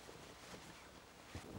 cloth_sail2.L.wav